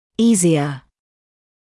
[‘iːzɪə][‘иːзиэ]легче, проще по сложности